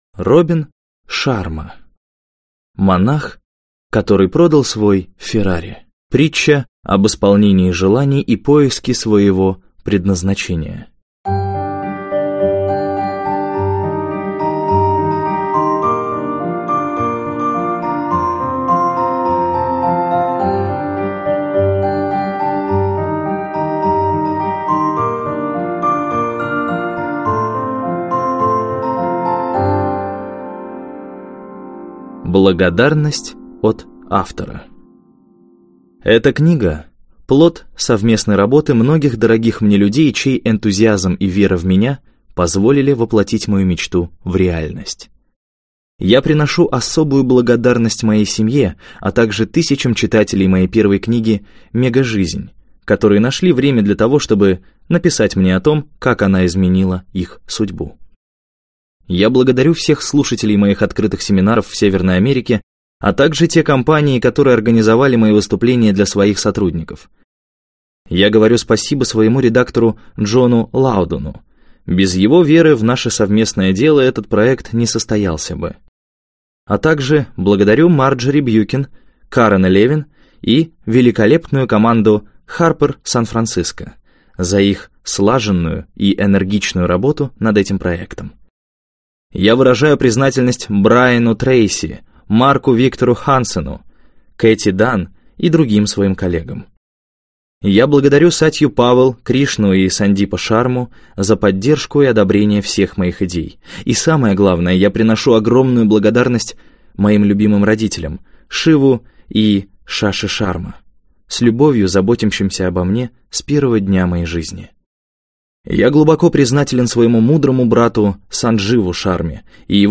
Аудиокнига Монах, который продал свой «феррари» - купить, скачать и слушать онлайн | КнигоПоиск